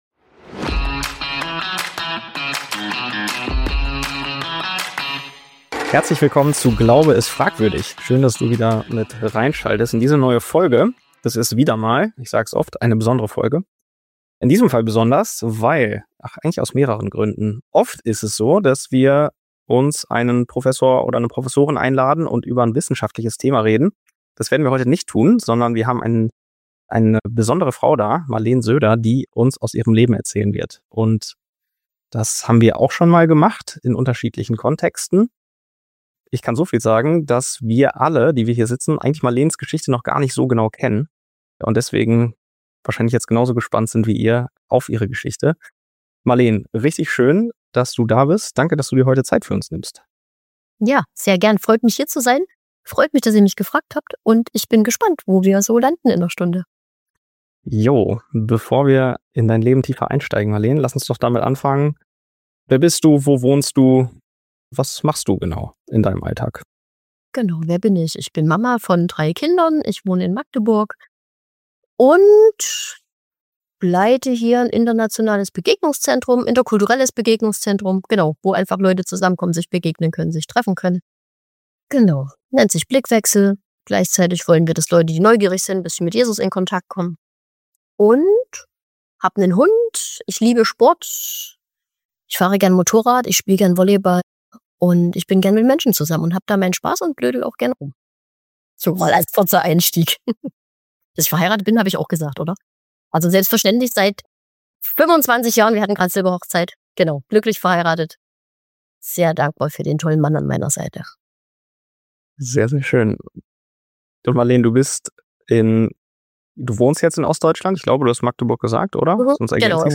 Was Trauma, Glaube und echte Heilung miteinander zu tun haben – darüber reden die drei offen und ohne Schönfärberei.